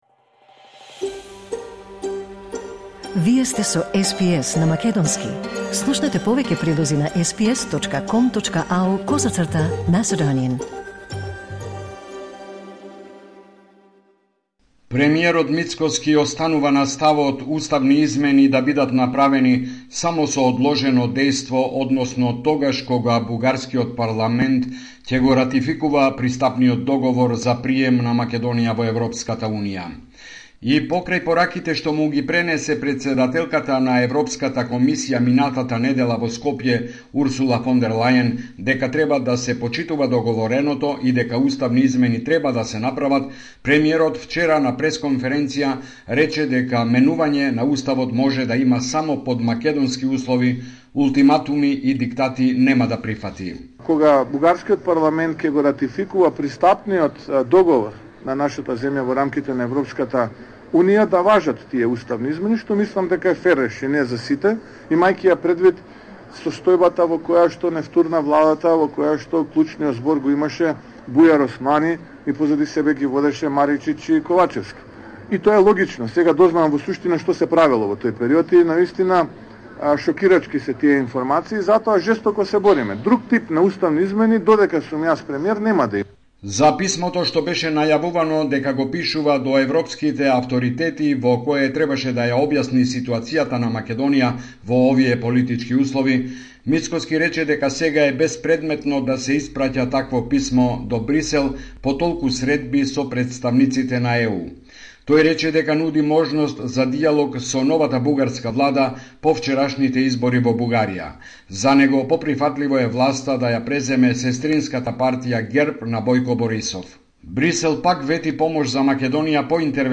Homeland Report in Macedonian 28 October 2024